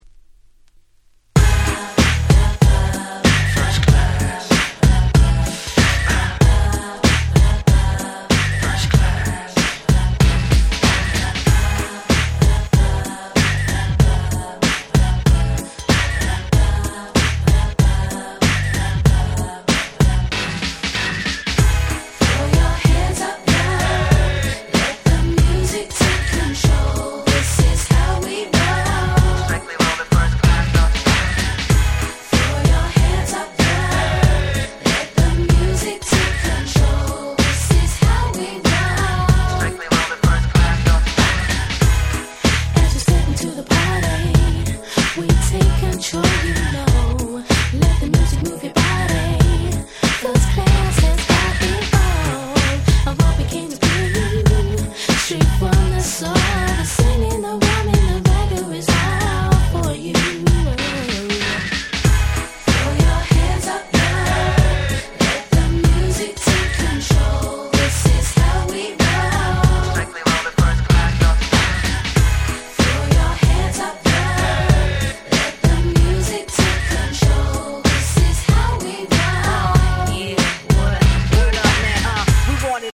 98' Nice EU R&B !!